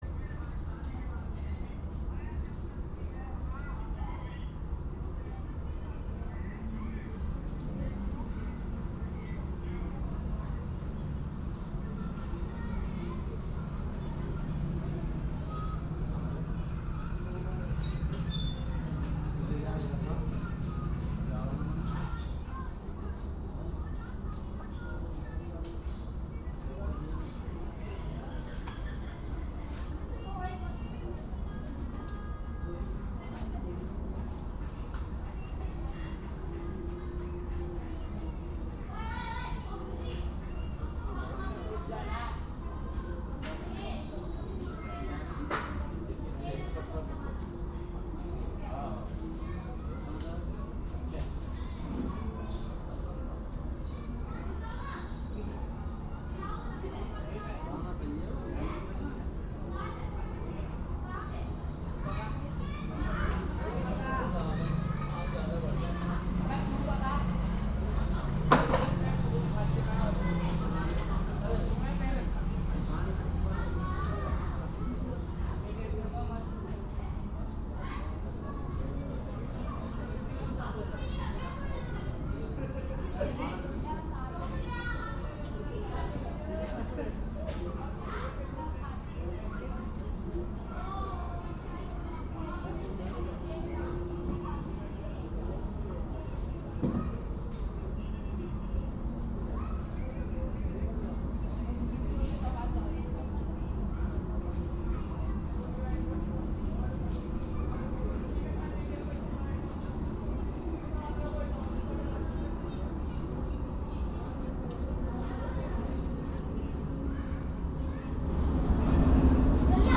Live from Soundcamp: soundcamp radio - Radio With Palestine (Audio) Dec 17, 2024 shows Live from Soundcamp Live transmission by the Soundcamp Cooperative Play In New Tab (audio/mpeg) Download (audio/mpeg)